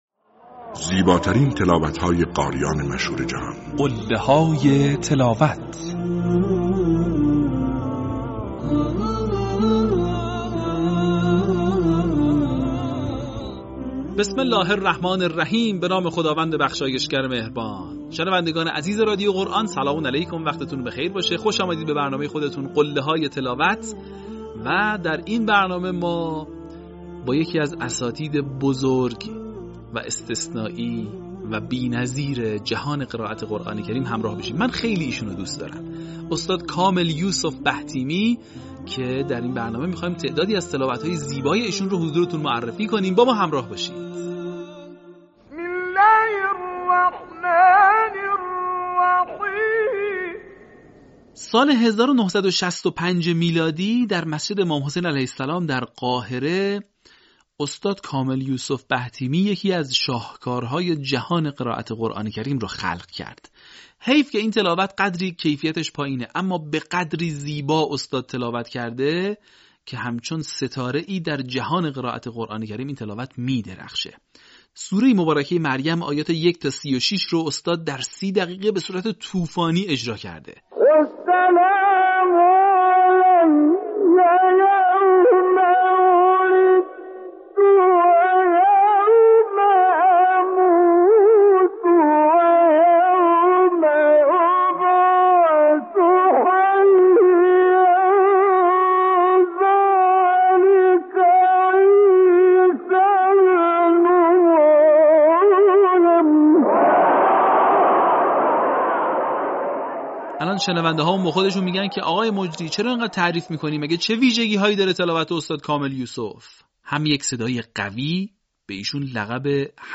در قسمت ششم، فرازهای شنیدنی از تلاوت‌های به‌یاد ماندنی استاد کامل یوسف بهتیمی را می‌شنوید.
برچسب ها: کامل یوسف بهتیمی ، تلاوت ماندگار ، قله های تلاوت ، قاریان مصری